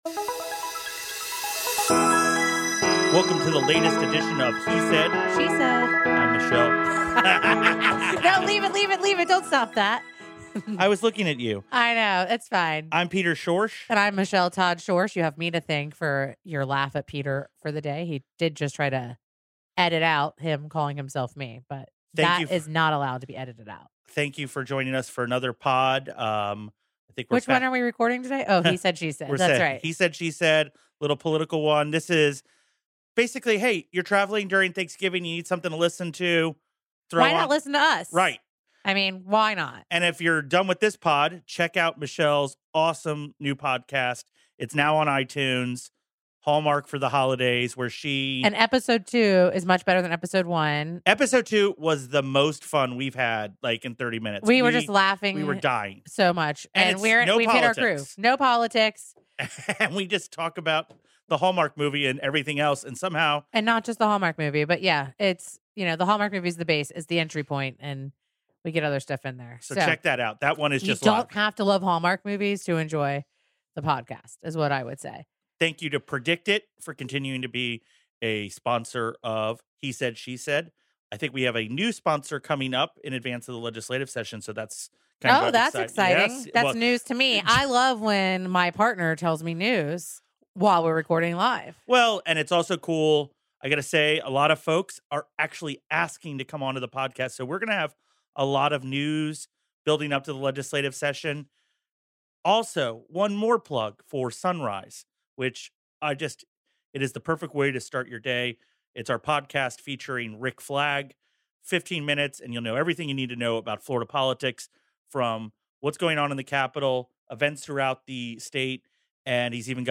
They are joined by State Senator Keith Perry, who represents Florida's 8th district. Perry discusses the components of his music education incentive pilot program and how transformative he believes it could be for elementary students throughout Florida.